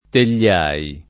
[ tel’l’ # i ]